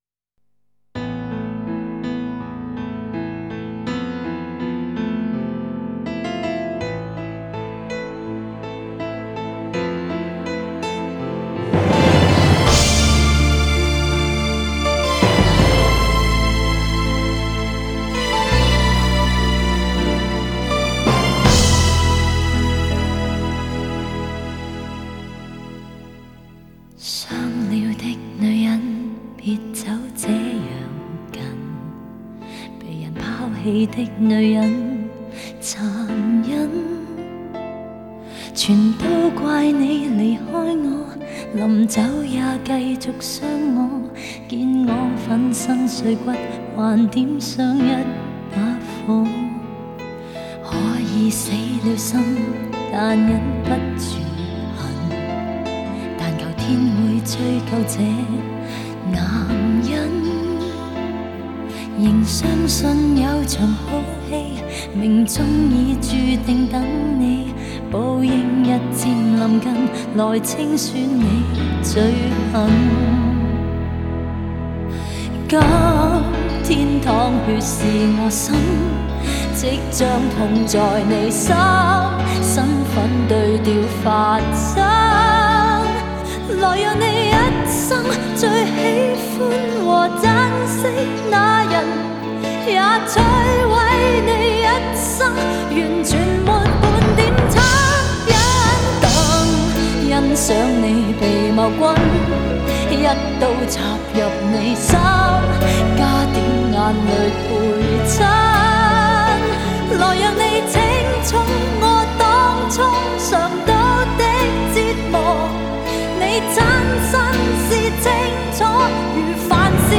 怀旧粤语情歌